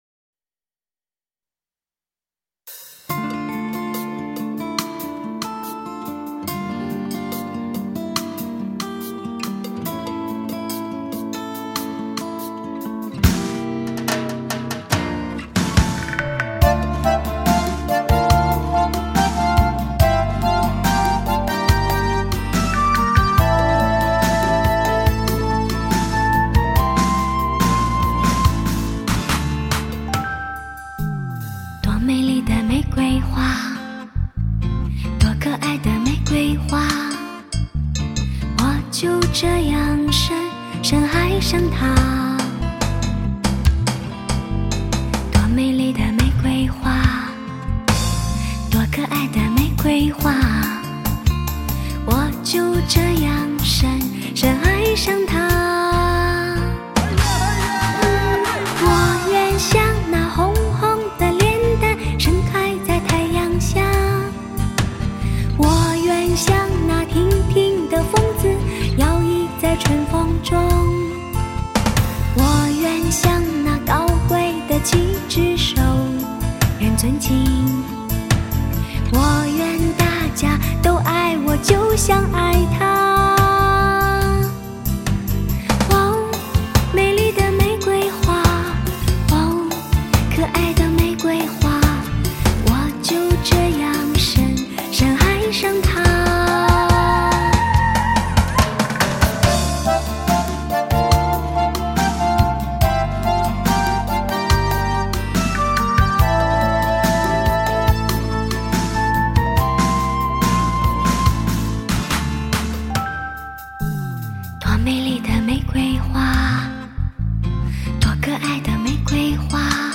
犹如冬日暖阳、夏日流水的最佳人声韵味天碟